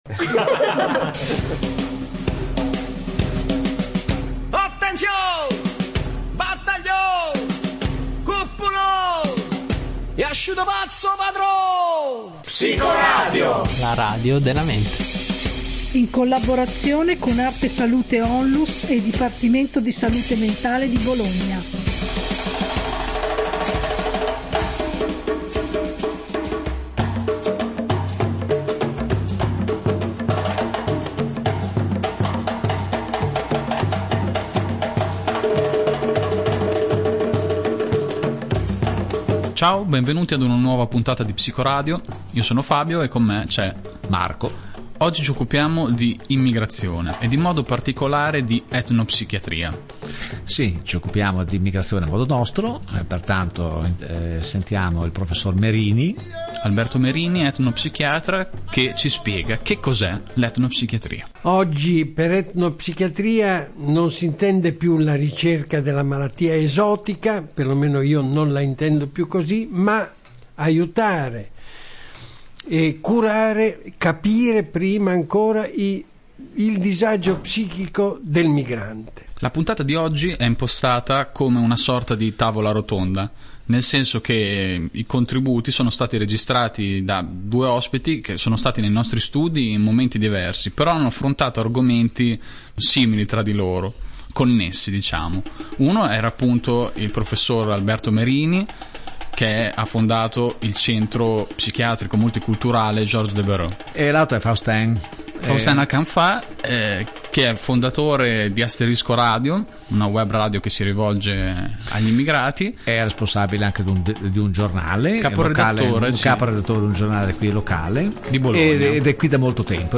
Clicca qui per ascoltare la puntata Immigrazione, etnopsichiatria e rituali di guarigione In questa puntata Psicoradio propone una tavola rotonda virtuale sull’etnopsichiatria e le altre cure possibili per affrontare il disagio psichico del migrante.